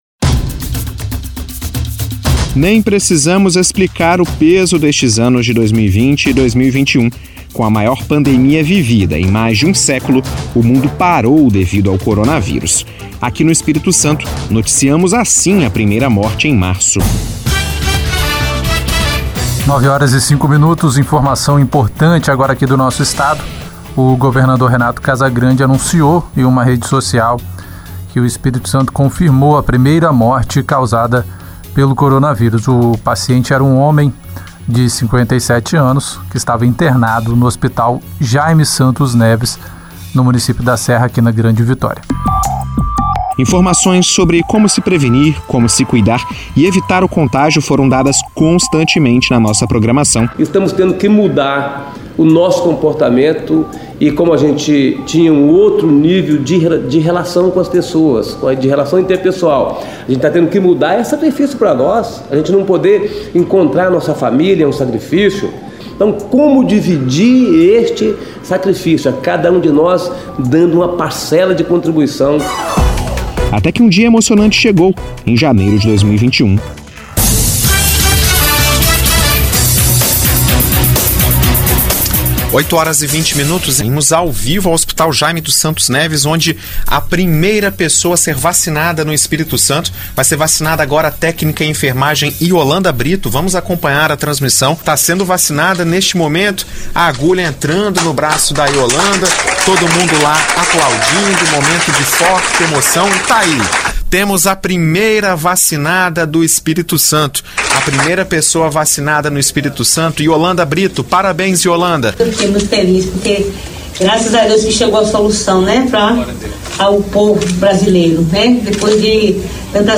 ESPECIAL-5-ANOS-2ª-MATÉRIA-1.mp3